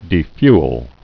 (dē-fyəl)